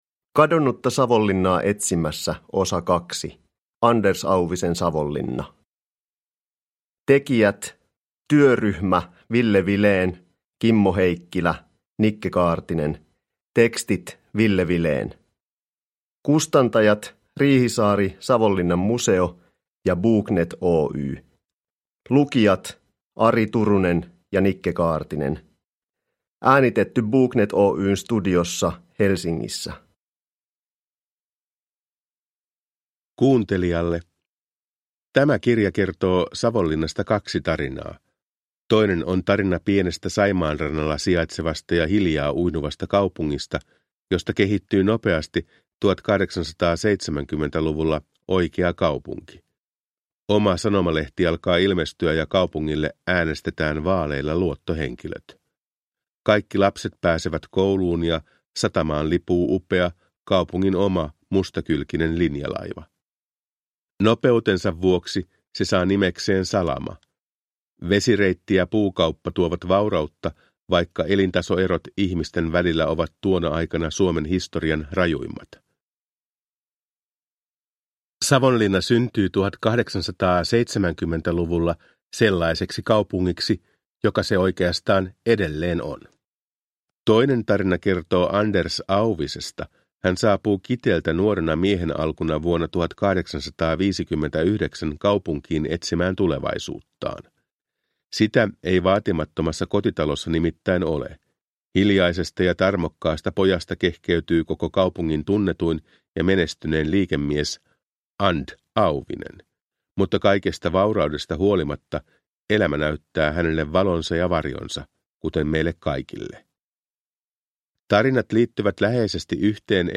Kadonnutta Savonlinnaa etsimässä osa 2 – Ljudbok